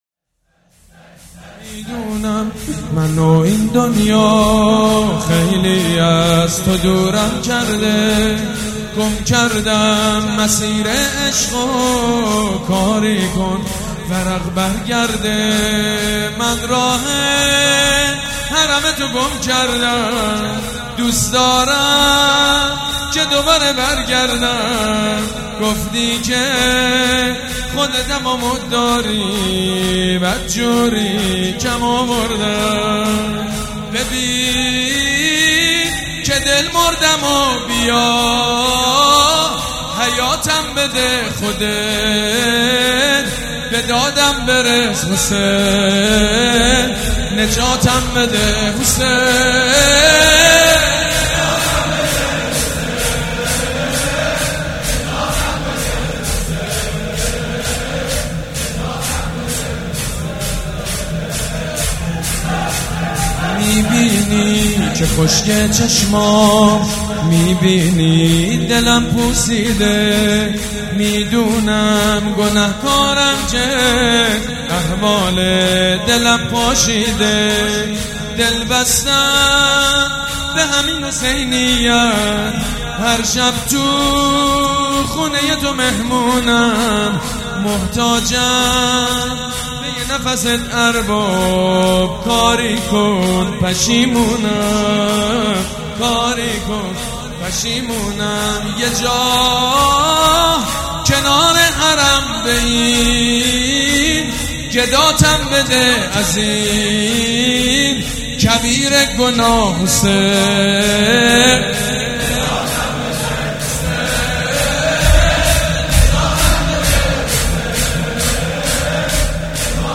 شور
مداح
حاج سید مجید بنی فاطمه
شهادت امام صادق (ع)